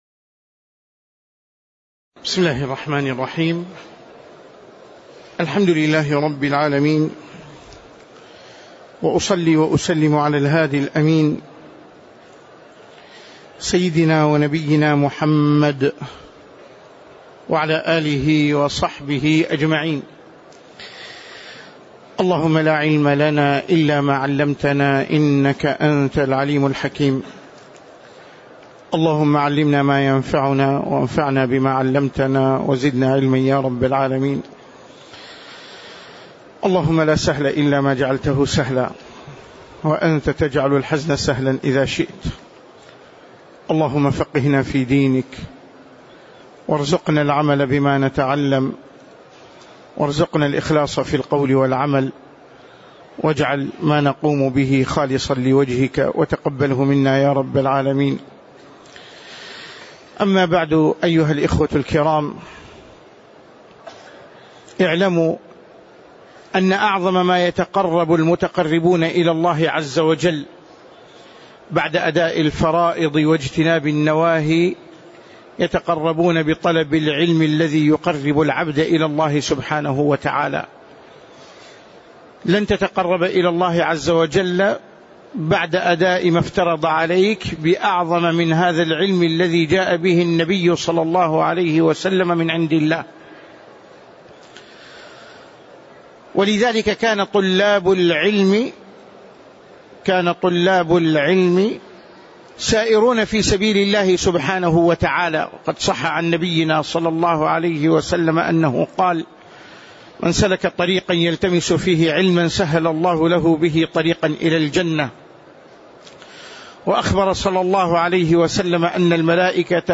تاريخ النشر ١ محرم ١٤٣٨ هـ المكان: المسجد النبوي الشيخ